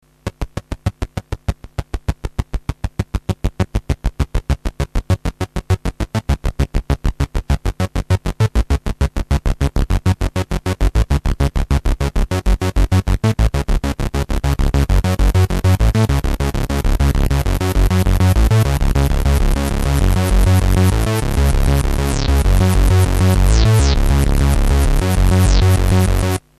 All done live demonstrating how easy (and fun) it is to get some great riffs.
adjusting gate length manually
arp_gate.mp3